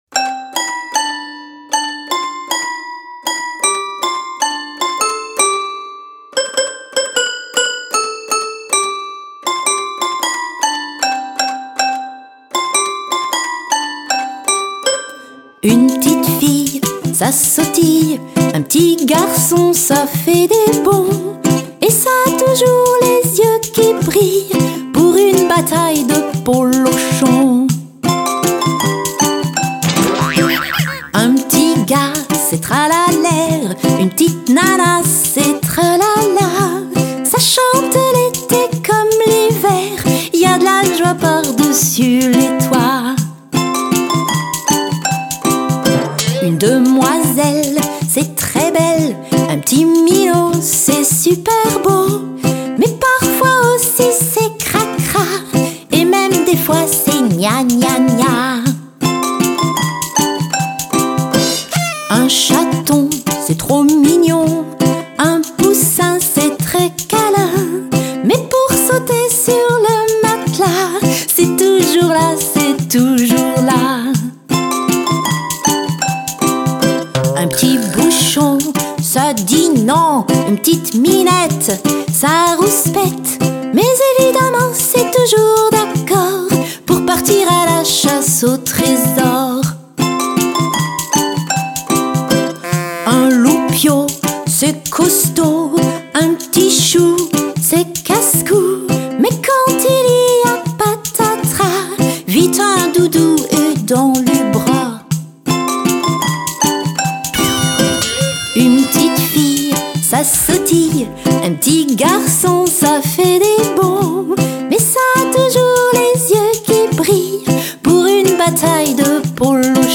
Une chanson du spectacle : « Les enfants »